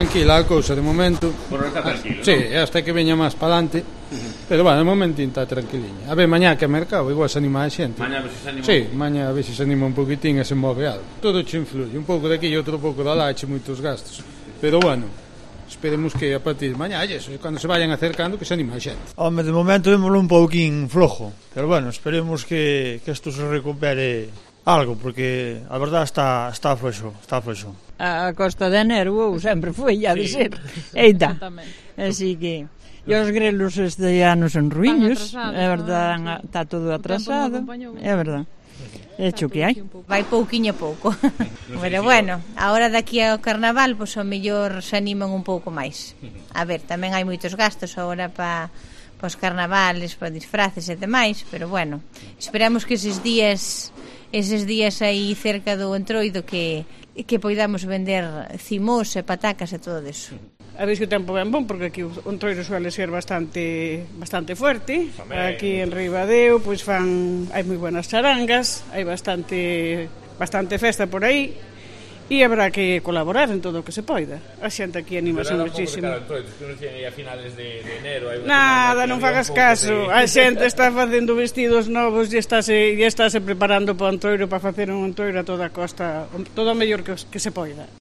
Encuesta realizada en la Plaza de Abastos de Ribadeo el 5 de febrero de 2019, justo un mes antes del Martes de Entroido